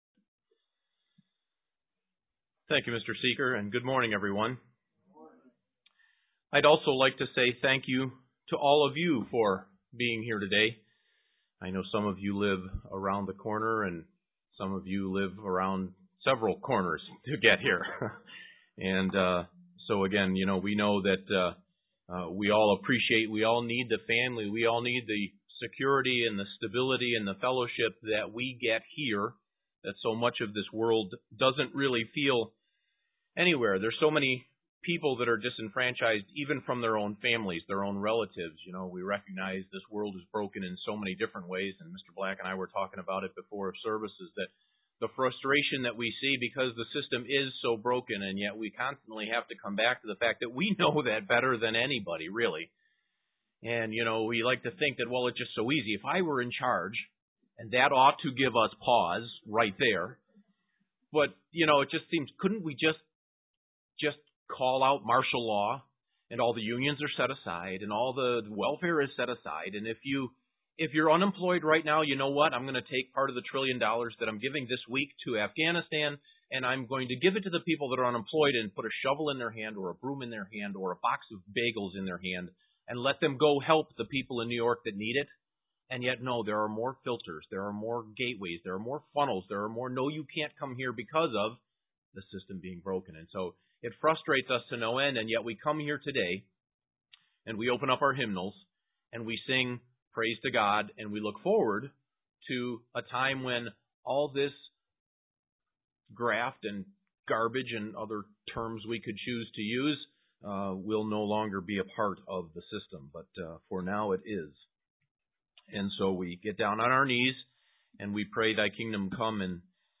Given in Elmira, NY
Print Whose names are written in the Book of Life UCG Sermon Studying the bible?